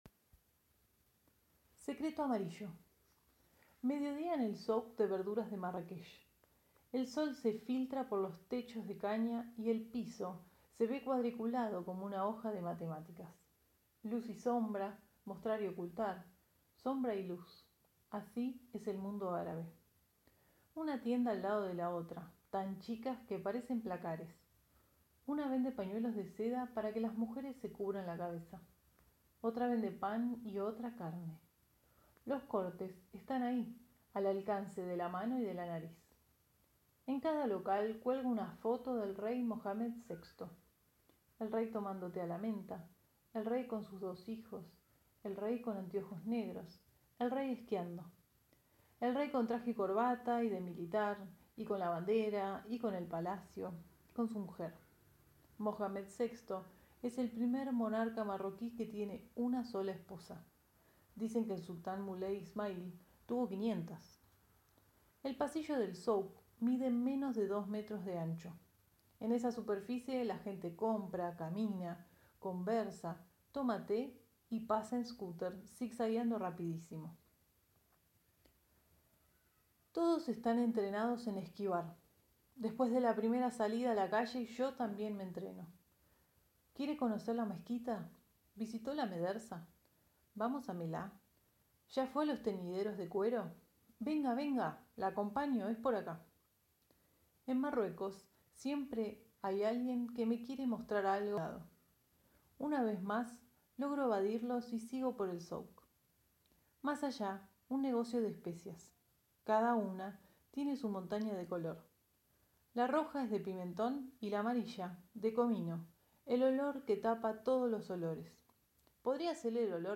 «Secreto amarillo» cuento